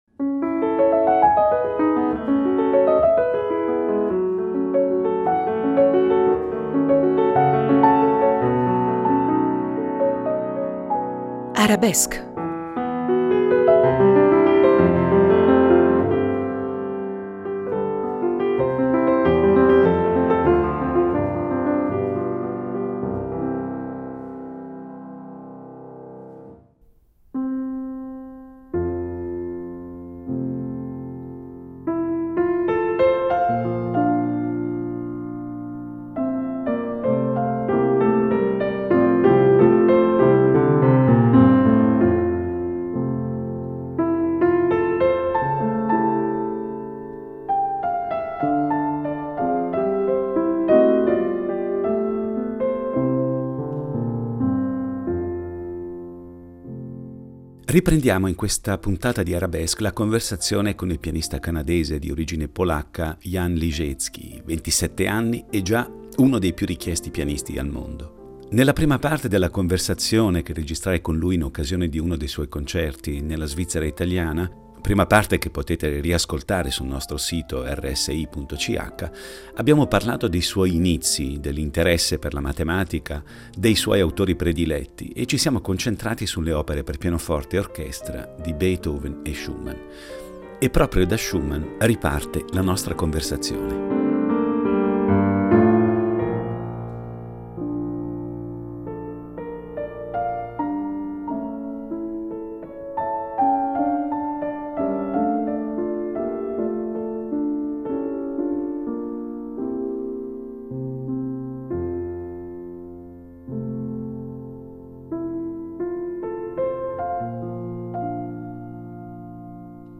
Reg. Lugano (RSI, Studio 5.0)